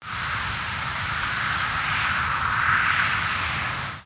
Golden-crowned Kinglet Regulus satrapa
Also, a high, descending "tsu".
"Tsu" and "see" calls from foraging bird.